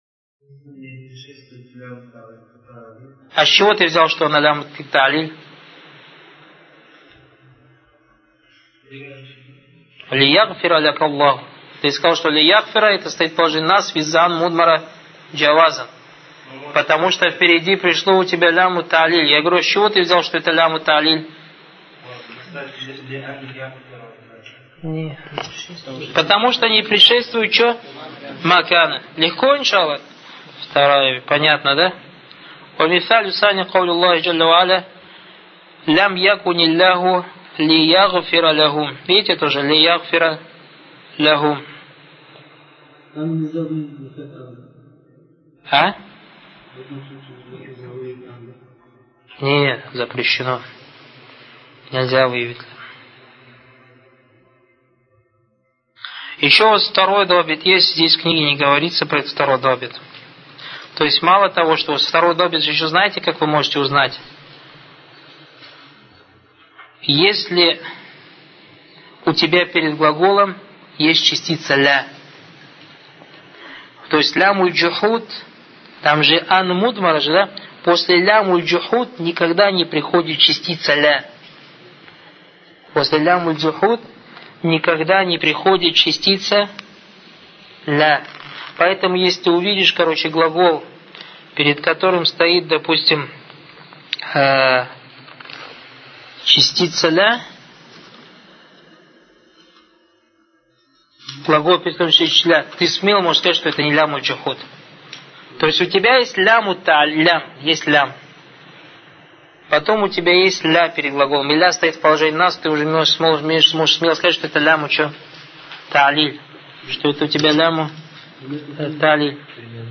المحاضر: محمد محيى الدين عبد الحميد